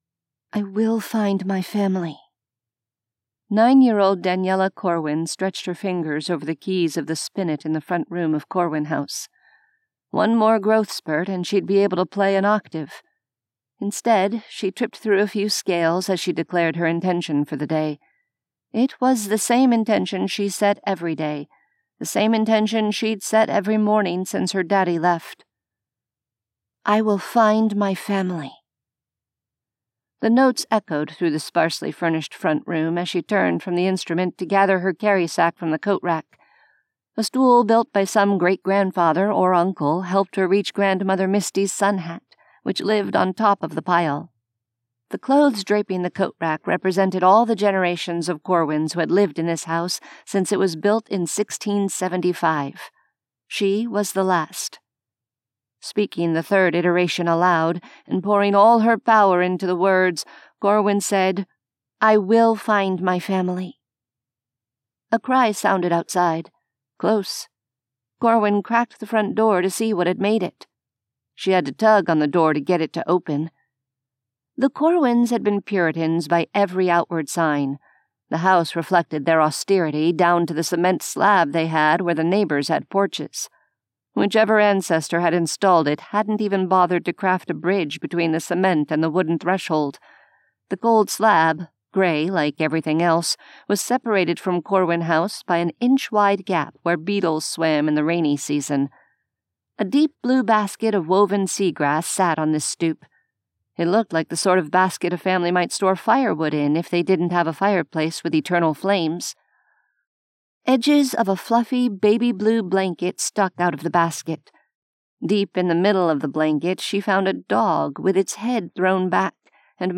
A sampling of audiobooks